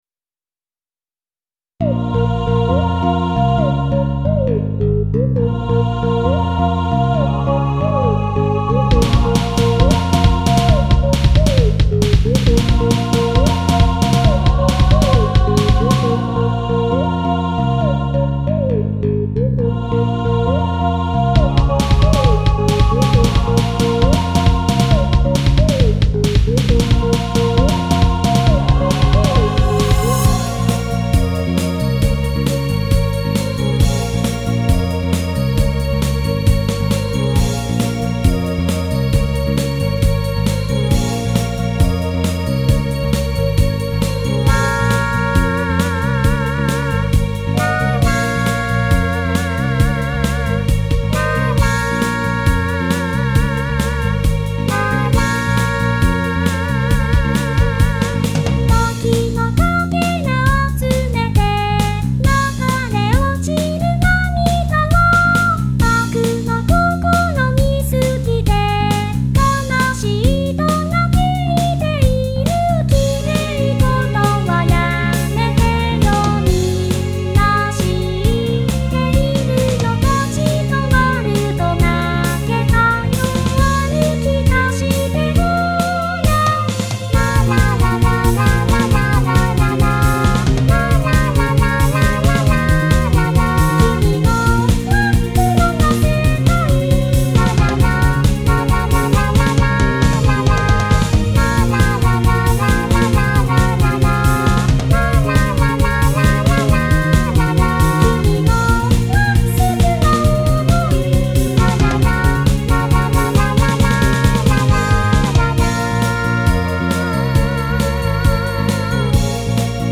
ボーカルの初音ミクがだめだめなので、鏡音兄妹を償還しようと思う。